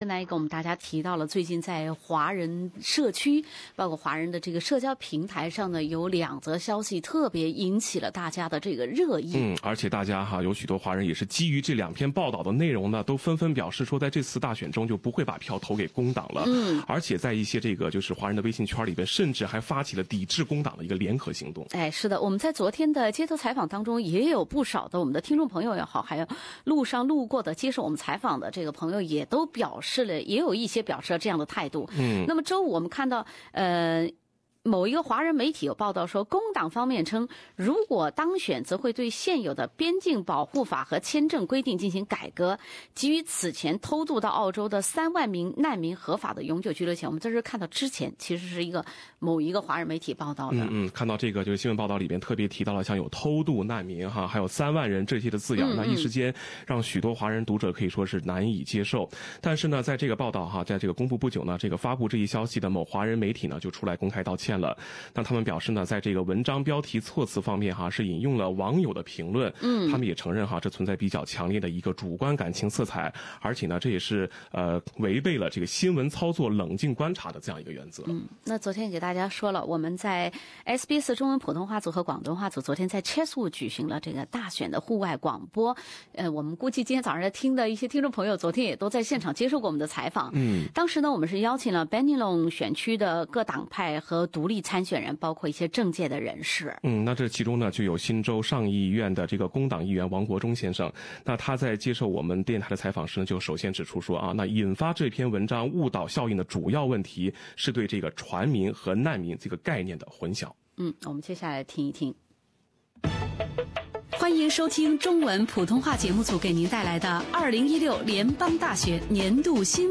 Ernest Wong (middle) at SBS Election Exchange